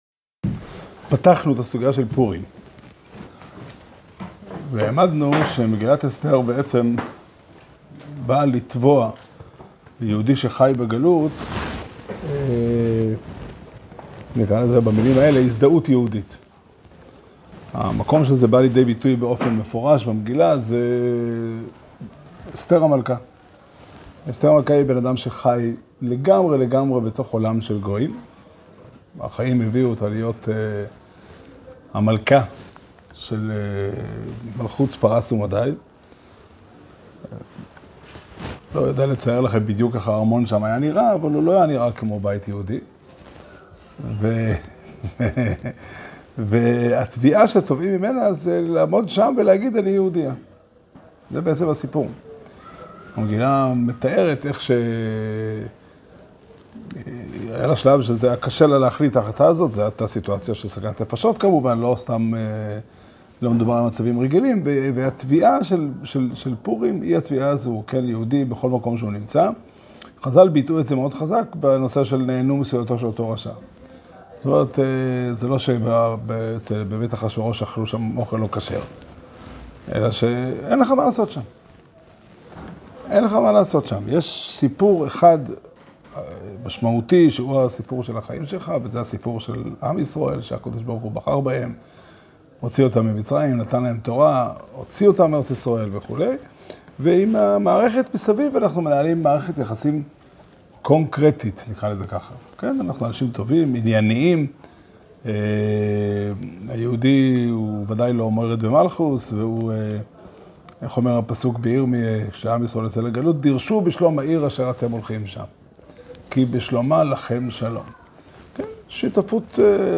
שיעור שנמסר בבית המדרש פתחי עולם בתאריך ב' אדר ב' תשפ"ד